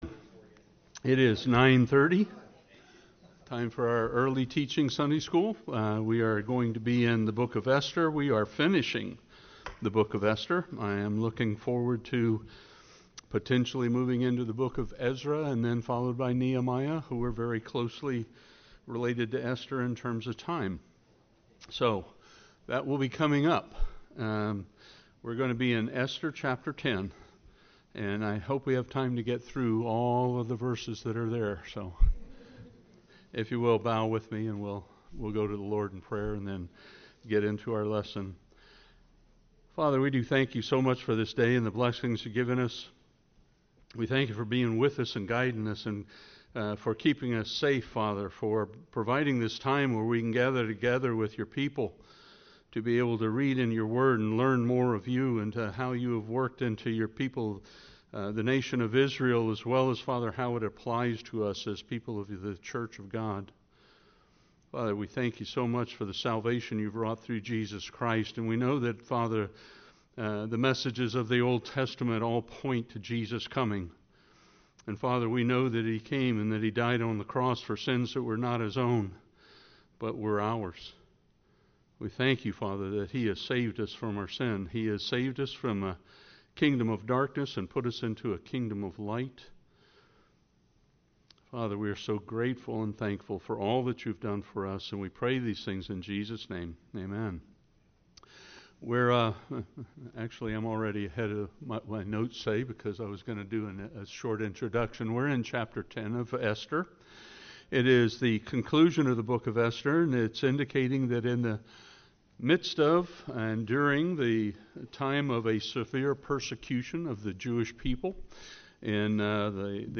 Grace Bible Chapel Non Denominational bible church verse-by-verse teaching
Service Type: Sunday School